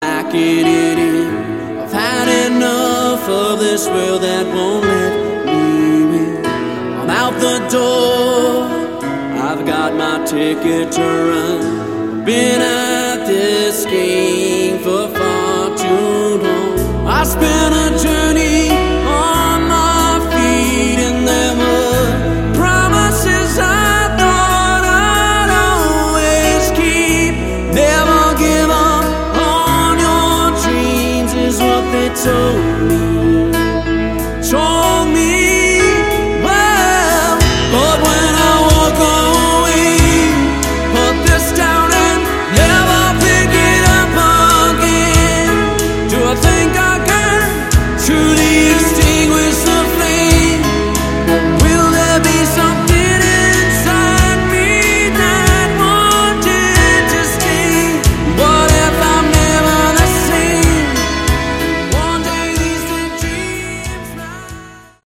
Category: AOR
vocals
guitars
bass
drums